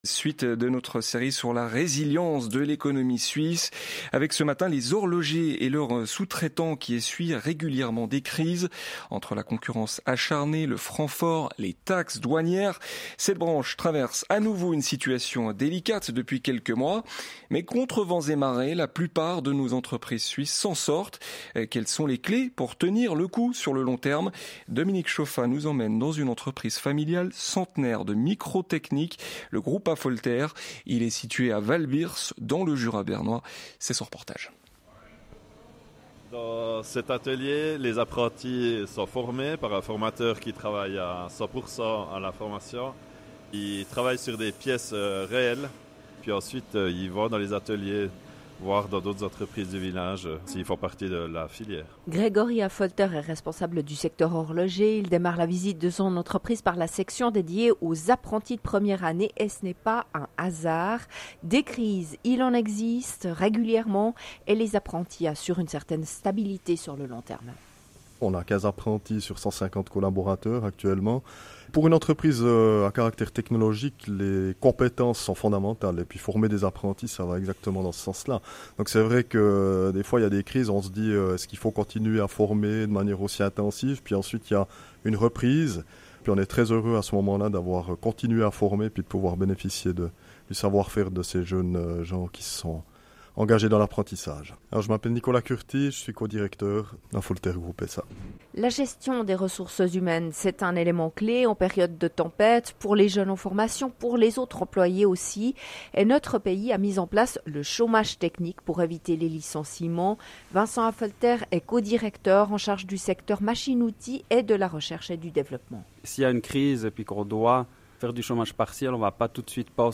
Face aux défis économiques actuels, l’horlogerie suisse fait preuve de résilience. Nos trois directeurs partagent leur vision au micro de la RTS dans La Matinale.
Reportage « RTS » 30.12.2025